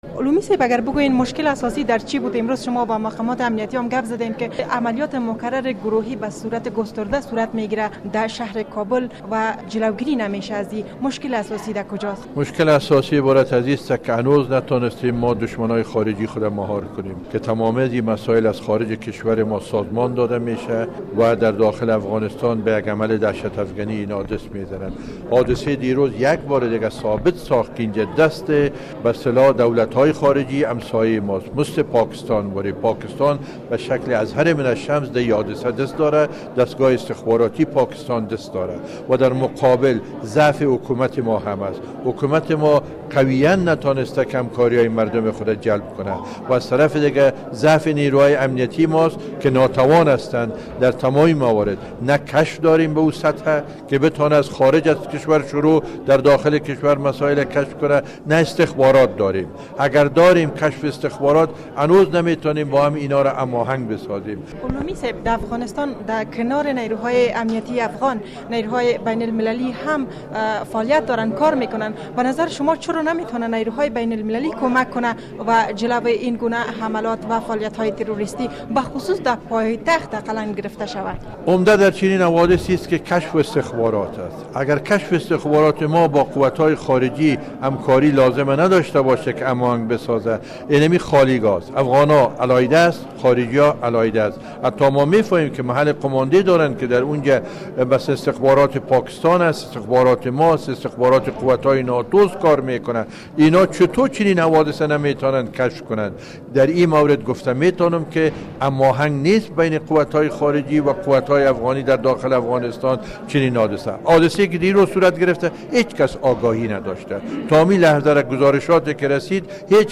مصاحبه با نورالحق علومی عضو پارلمان و یک کارشناس مسایل سیاسی در مورد حملات اخیر تروریستی شهر کابل